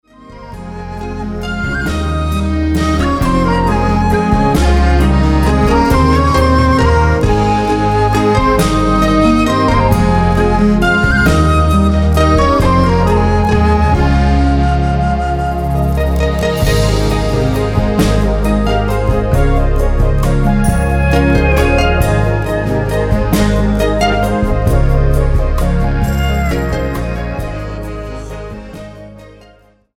Schlagzeug
Saxophone und Klarinetten
Perkussion